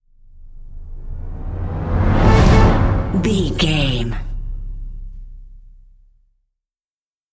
Epic / Action
Fast paced
In-crescendo
Aeolian/Minor
A♭
Fast
strings
drums
orchestral hybrid
dubstep
aggressive
energetic
intense
bass
synth effects
wobbles
driving drum beat